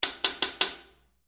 Knocking|Knocks | Sneak On The Lot
Door Knocks; Glass / Wood, Single